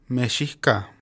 The Mexica (Nahuatl: Mēxihcah, Nahuatl pronunciation: [meːˈʃiʔkaḁ]